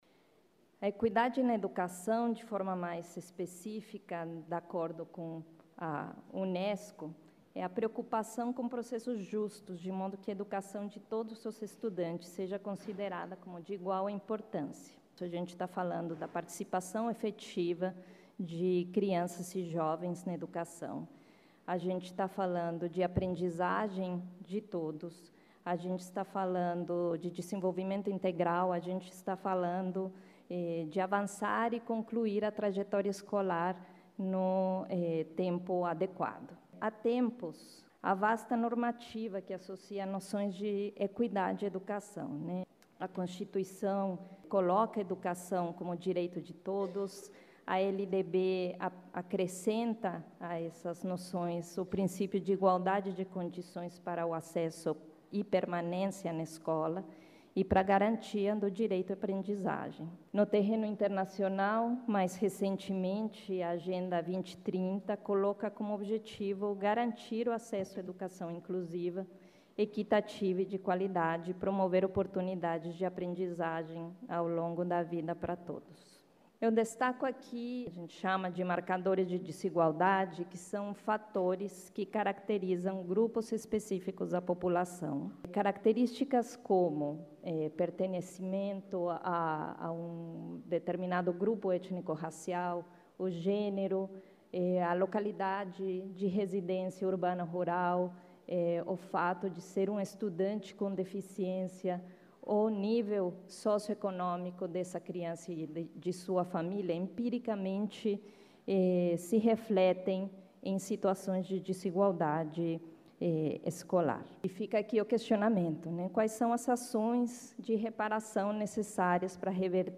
IV Sined e III Encontro de Promotores e Promotoras de Justiça da Educação - áudios dos participantes
Painel "Educação e intersetorialidade de políticas públicas"